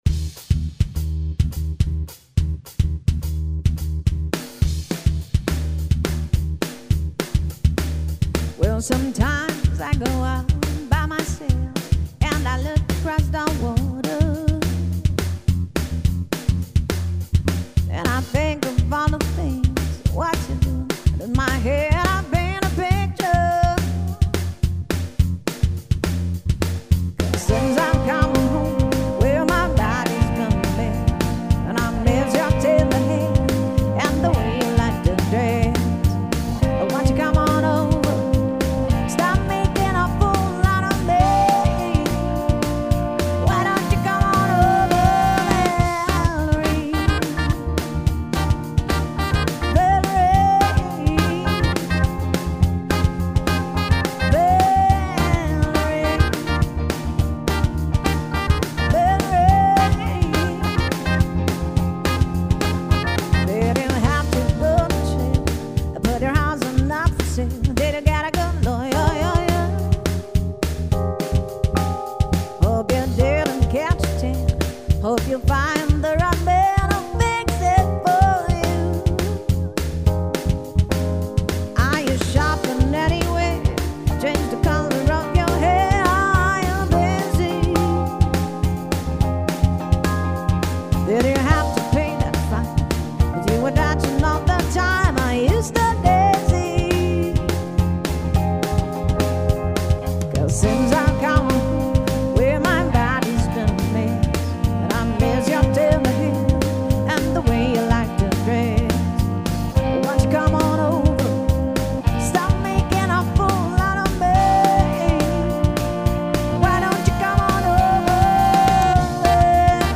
Internationale Tanz- und Unterhaltungsband